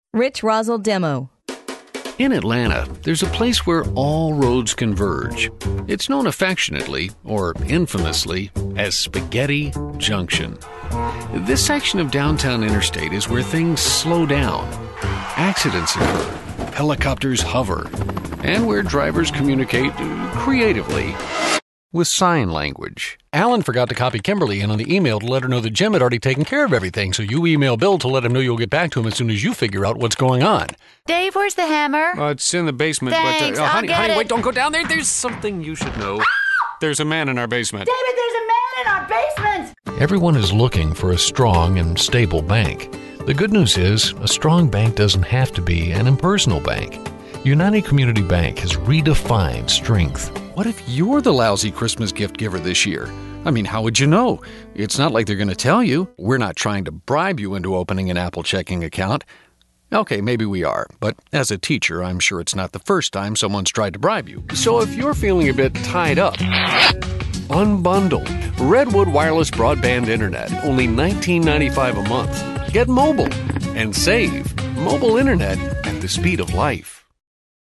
Commercial Character Narration On-Hold Audiobook And since it's said that beauty is in the eye of the beholder, here are a couple of videos in which my voice has been used.